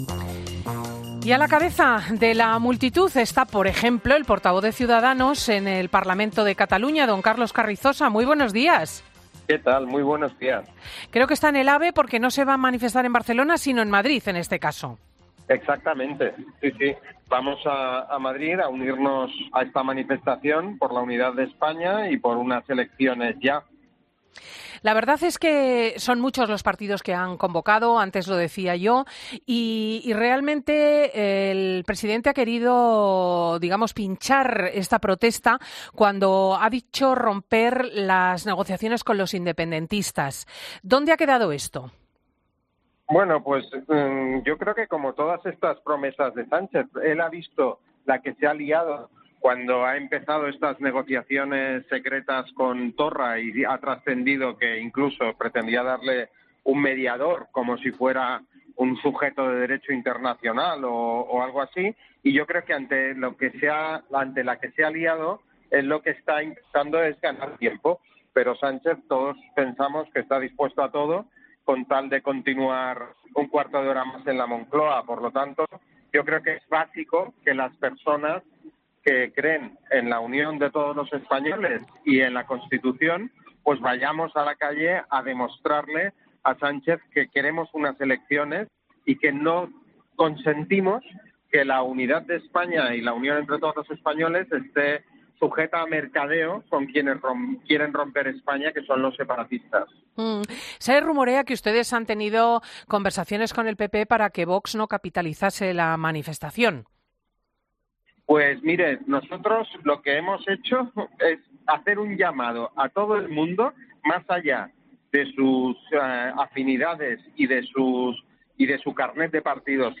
El secretario de Organización autonómico de Ciudadanos, Carlos Carrizosa, que atiende a Cristina López Schlichting en el AVE camino de la manifestación en Madrid, es rotundo: " No consentimos que la unidad de España esté sujeta a mercadeo.